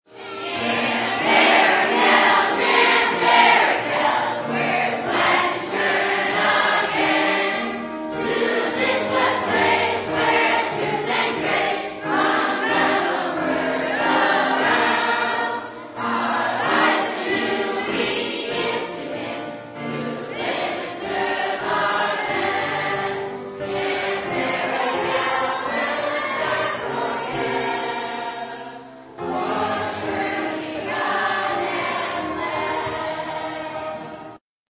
Singing at Barakel
Here’s a group of summer campers singing the camp song in West Side Chapel.